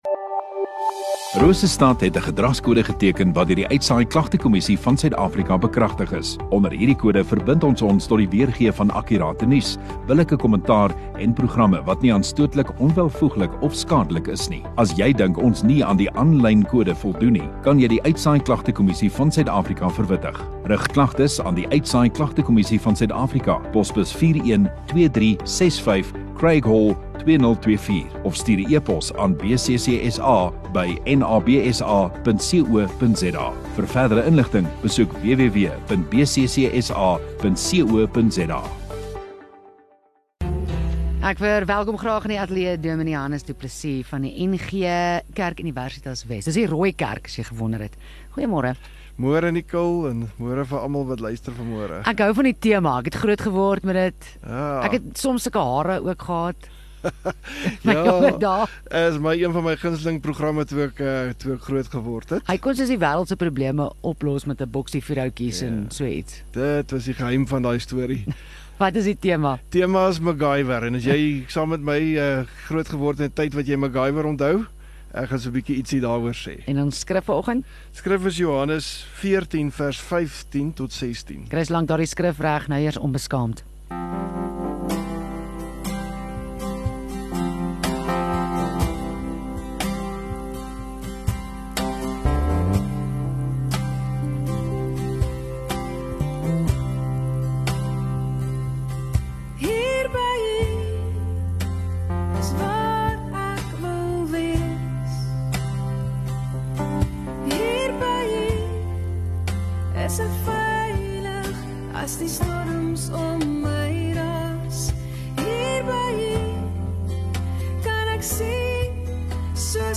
16 May Dinsdag Oggenddiens